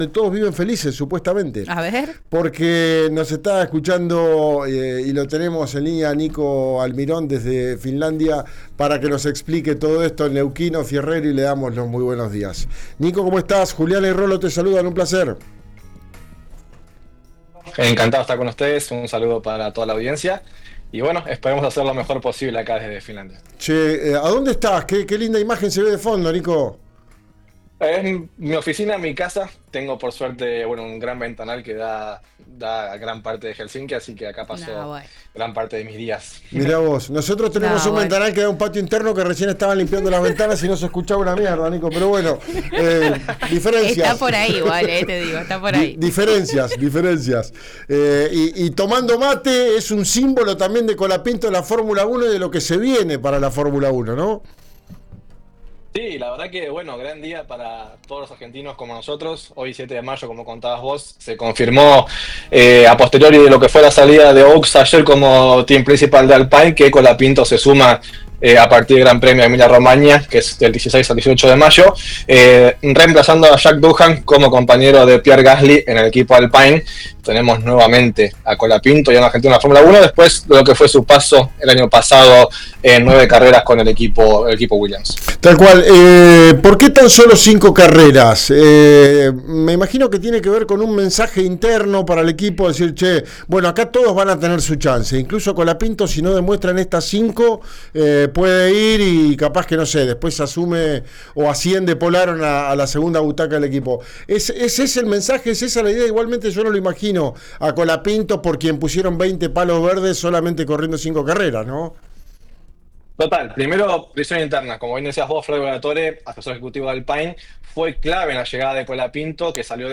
En una entrevista radial para el programa «Ya es tiempo»